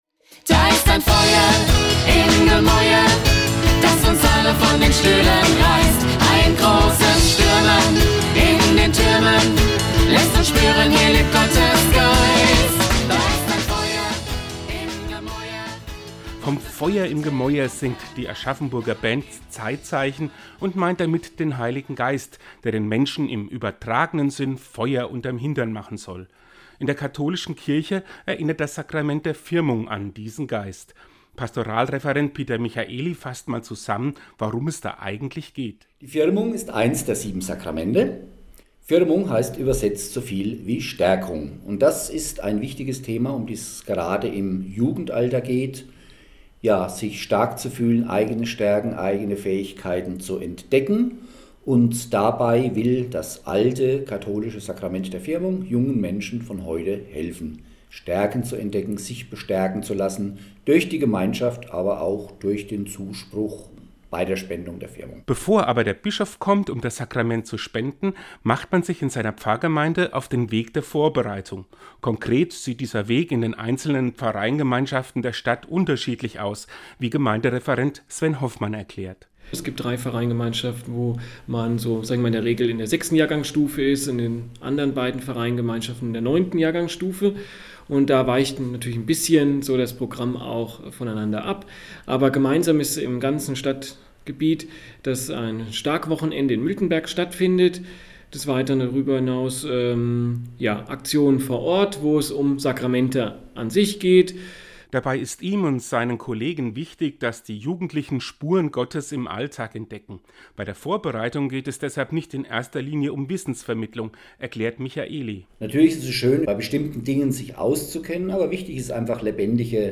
Seinen Radiobeitrag finden Sie unten als Download!